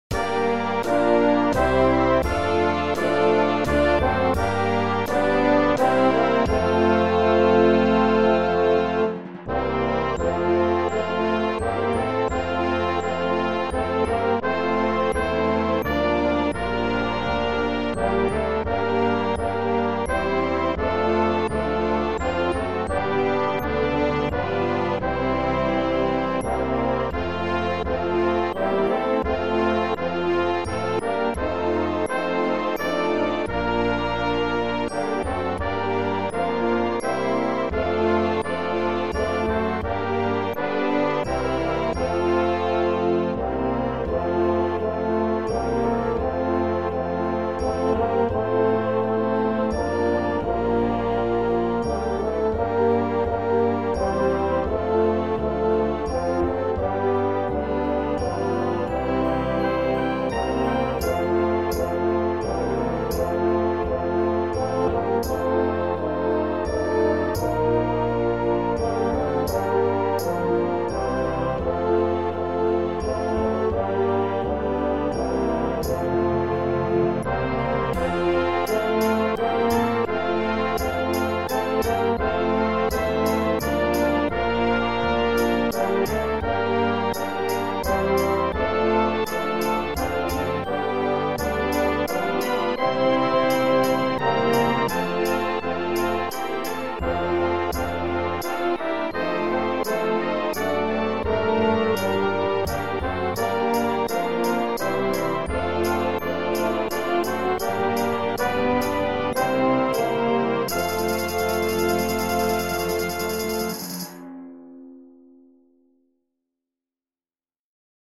79B1 Brass Band $20.00 **  Woodwind expansion Pack $5.00
(computer generated sound sample)